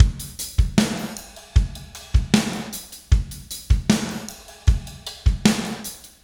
Spaced Out Knoll Drums 07.wav